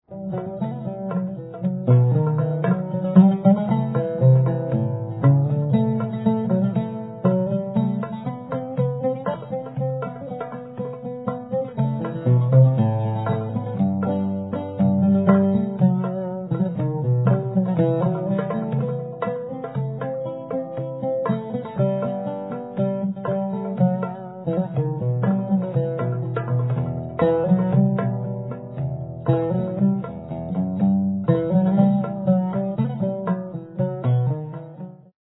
Middle Eastern Medley: